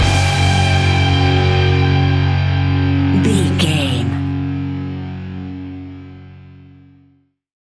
Ionian/Major
F♯
hard rock
heavy metal
instrumentals